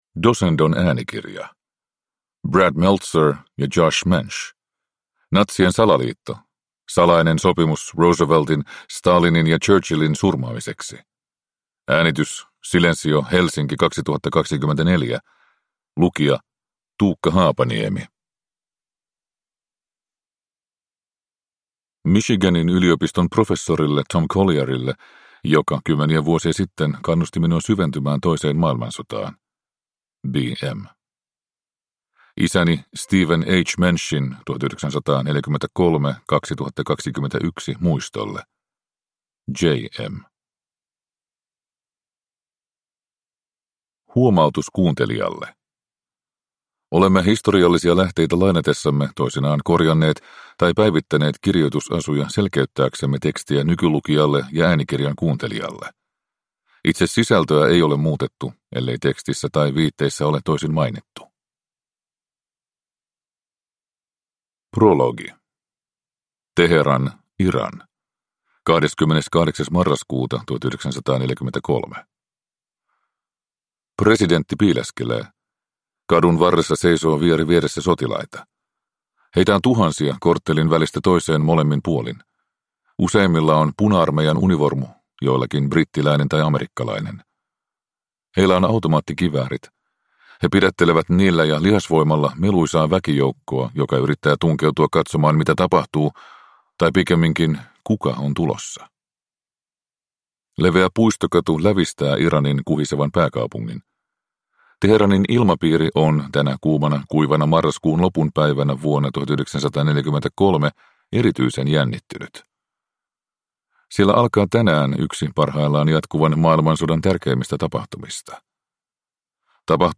Natsien salaliitto – Ljudbok